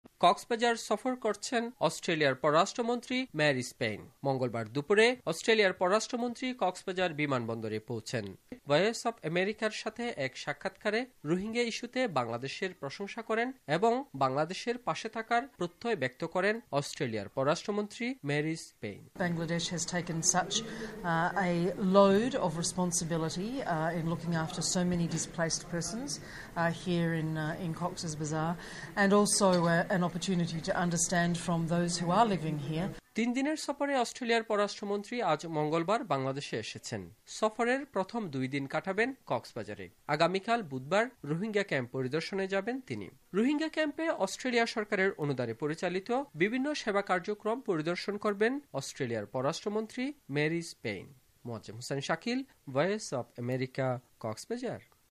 অস্ট্রেলিয়ার পররাষ্ট্রমন্ত্রী মেরিস পেইন ৩দিনের সফরে বাংলাদেশে পৌঁছেছেন। রোহিঙ্গা পরিস্থিতি পর্যবেক্ষণ করতে এখন তিনি কক্সবাজার সফর করছেন। কক্সবাজার থেকে বিস্তারিত জানাচ্ছেন